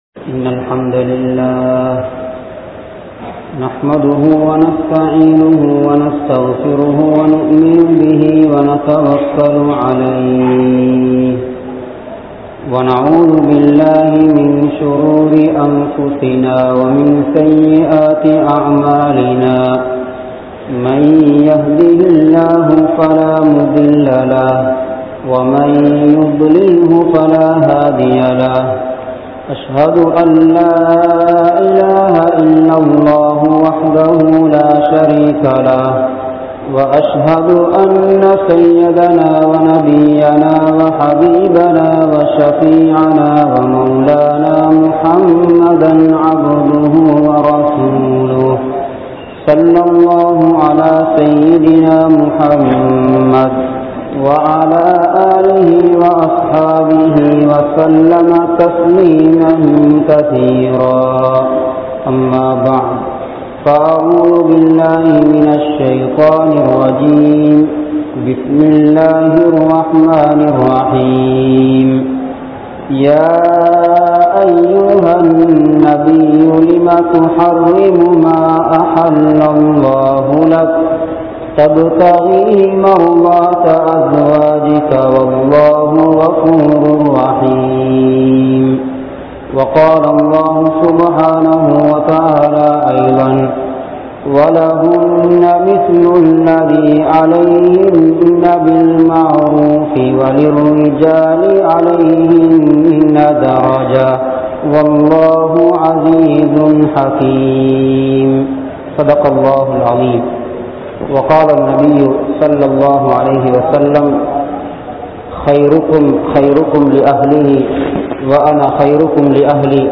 Santhoasamaana Kudumba Vaalkai (சந்தோசமான குடும்ப வாழ்க்கை) | Audio Bayans | All Ceylon Muslim Youth Community | Addalaichenai
Aluthgama, Dharga Town, Meera Masjith(Therupalli)